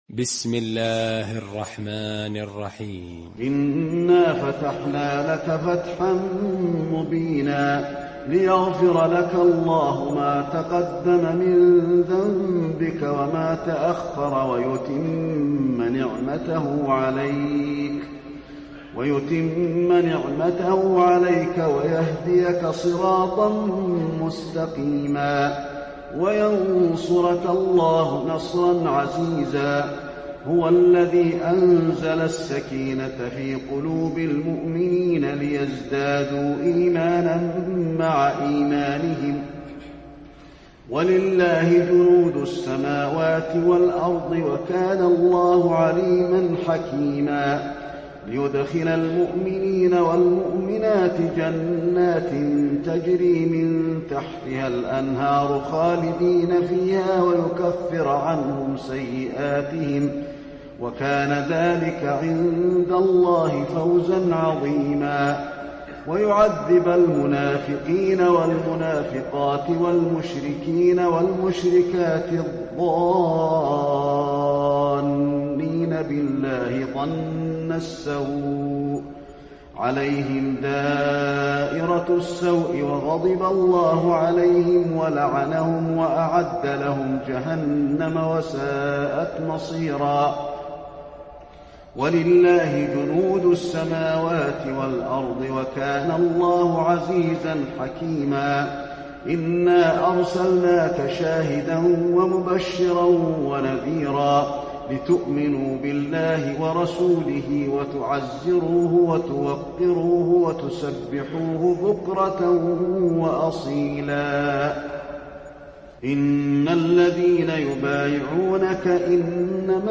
المكان: المسجد النبوي الفتح